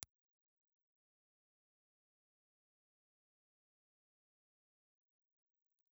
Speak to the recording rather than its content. Impulse Response file of a Reslo RBL/30 ribbon microphone. Reslo_RBL_30_IR.wav